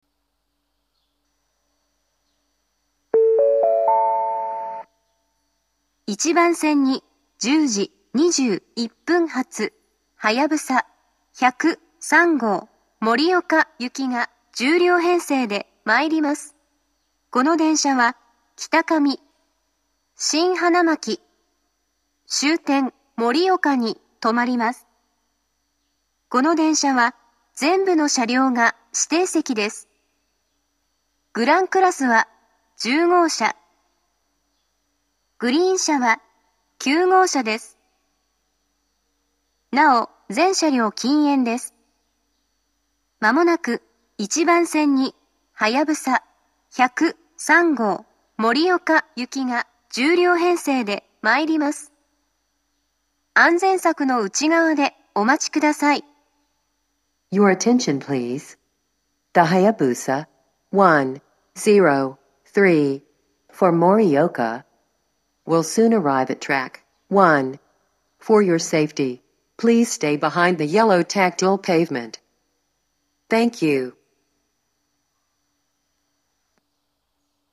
２０２１年２月上旬頃には、自動放送が合成音声に変更されました。
１番線接近放送